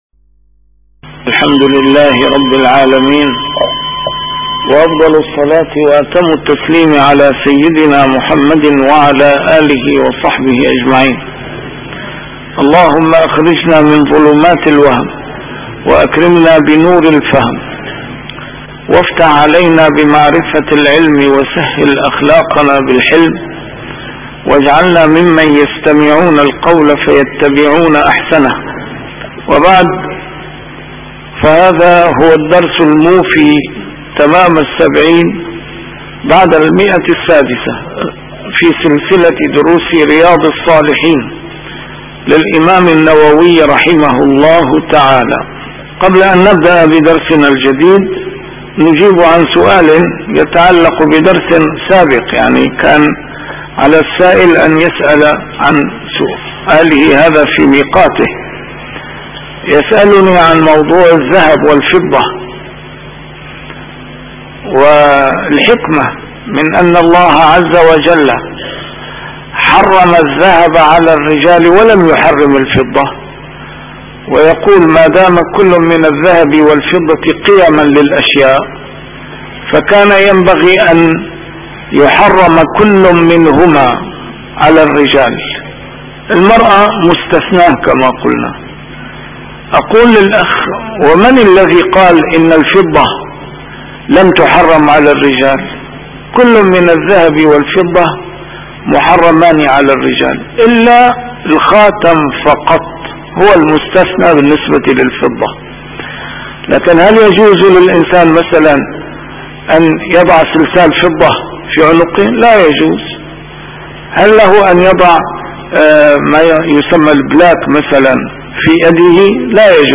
A MARTYR SCHOLAR: IMAM MUHAMMAD SAEED RAMADAN AL-BOUTI - الدروس العلمية - شرح كتاب رياض الصالحين - 670- شرح رياض الصالحين: آداب النوم والاضطجاع